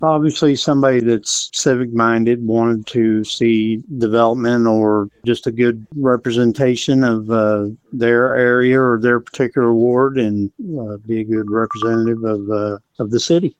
Mayor Jeff Braim spoke with KTLO, Classic Hits and the Boot News about the type of person they hope to find to serve in the seat.